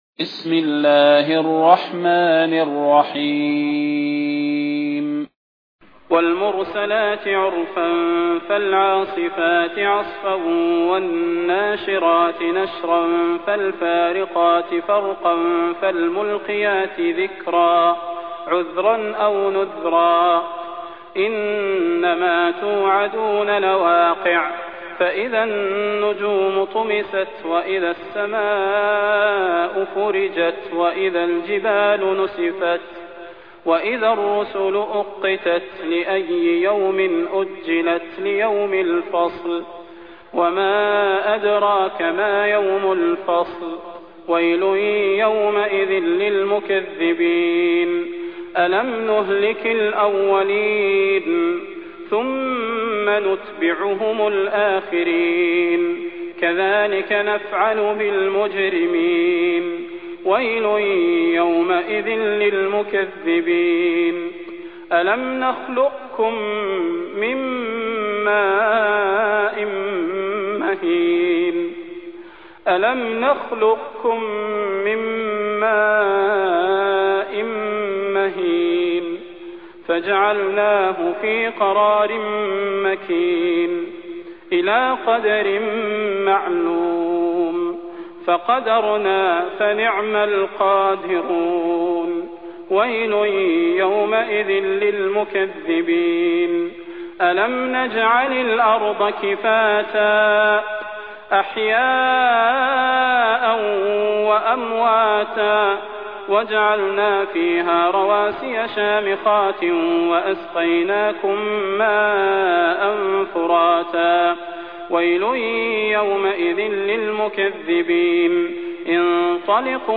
المكان: المسجد النبوي الشيخ: فضيلة الشيخ د. صلاح بن محمد البدير فضيلة الشيخ د. صلاح بن محمد البدير المرسلات